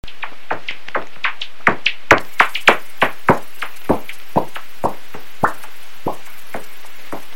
The sounds from the bat detector usually alternate between “smacks” and “tocks” at a fairly slow repetition rate which together sounds like a fairly irregular “chip-chop”.
Note the alternating types of call, which gives rise to the characteristic “chip-chop” sound from a heterodyne bat detector.
These  noctule calls as heard on a heterodyne bat detector set to 19kHz.